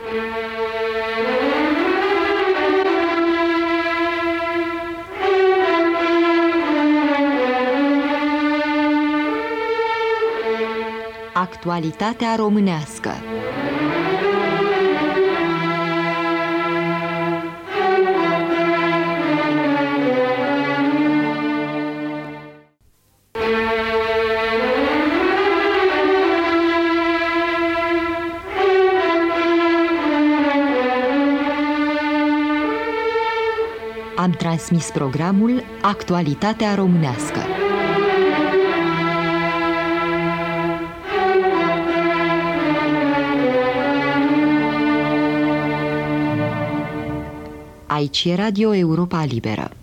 Genericul emisiunii fanion a Europei libere „Actualitatea românească”